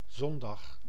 Ääntäminen
Ääntäminen France: IPA: [di.mɑ̃ːʃ] Tuntematon aksentti: IPA: /di.mɑ̃ʃ/ Haettu sana löytyi näillä lähdekielillä: ranska Käännös Ääninäyte Substantiivit 1. zondag {m} Suku: m .